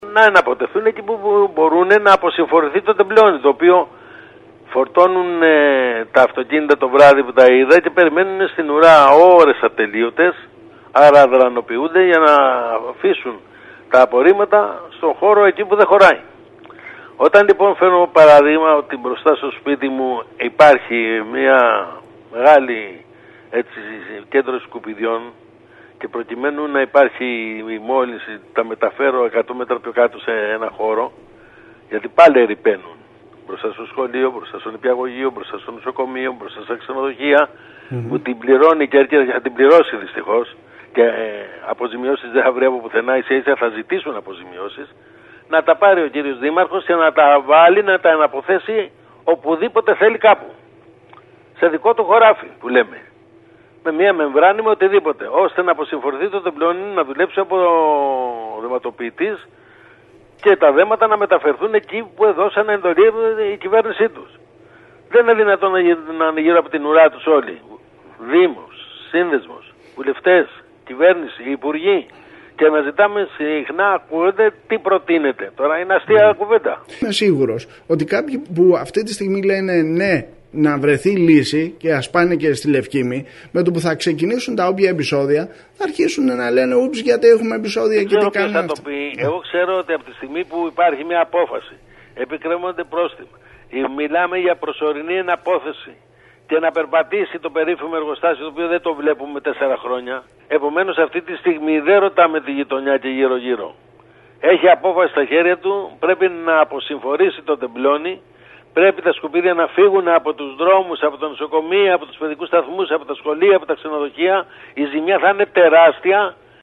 Την ανάγκη να απομακρυνθούν άμεσα οι όγκοι των απορριμμάτων από τους δρόμους της Κέρκυρας τόνισε, μιλώντας στο σταθμό μας, ο επικεφαλής της μείζονος μειοψηφίας στο Περιφερειακό Συμβούλιο Σπύρος Σπύρου.